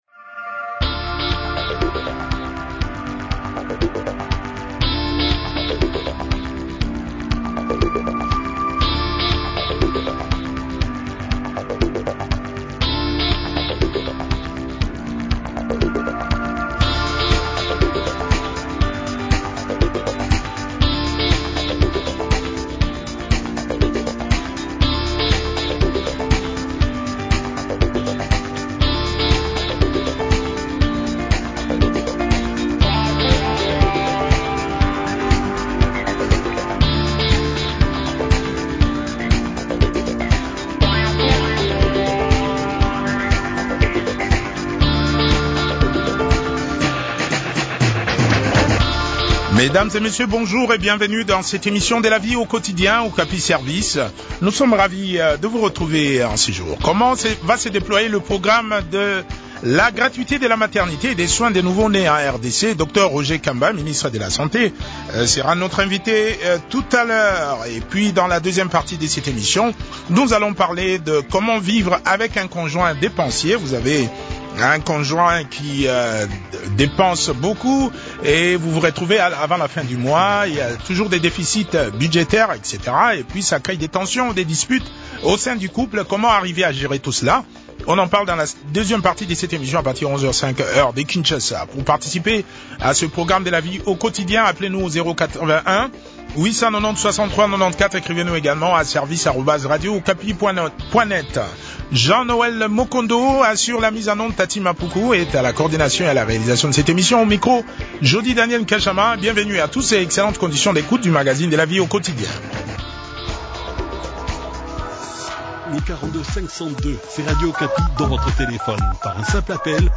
expert en santé publique.